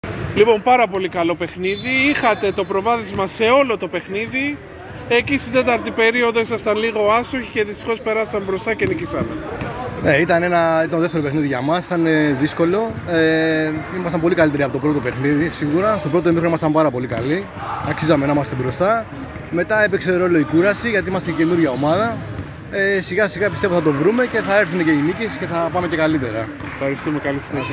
GAMES INTERVIEWS